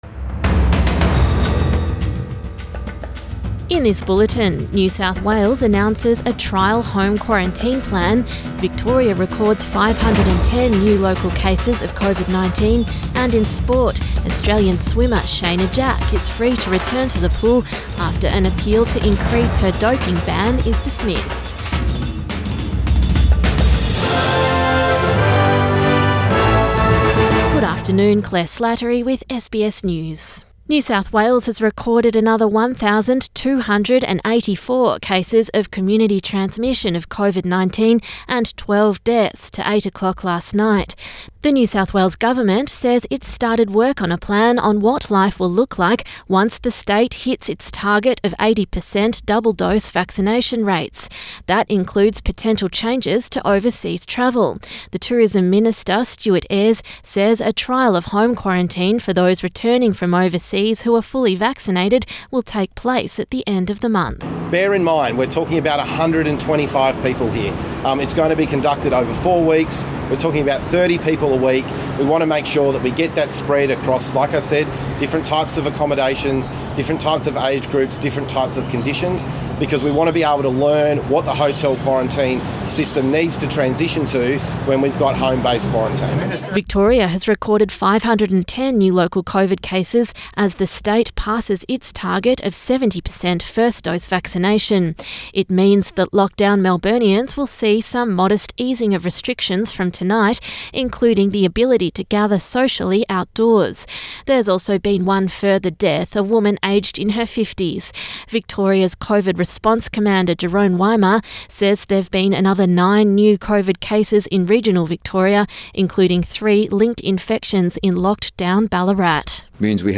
Midday bulletin 17 September 2021